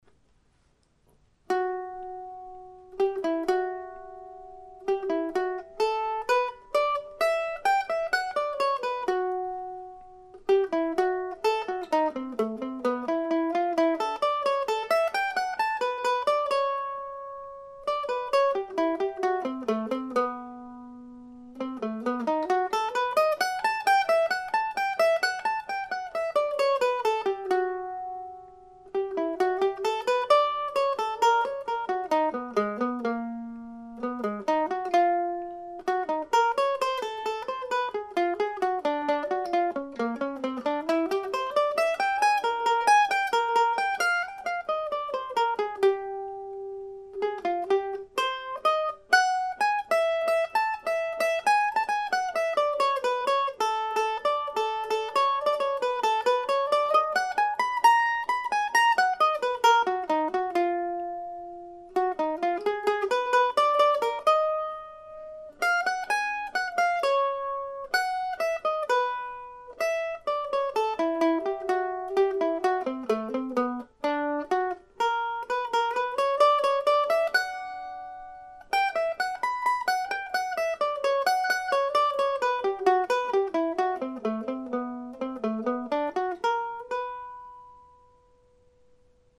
Back in 2005 I built a web page that contained links to recordings and music for a number of short solo mandolin pieces that I titled Deer Tracks.